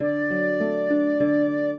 flute-harp
minuet15-3.wav